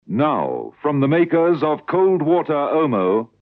You know you're listening to a Springbok Radio programme by the distinctive voices of the announcers that can be heard at the top and tail of each episode...
There are three versions of this pre-recorded announcement heard in the surviving episodes.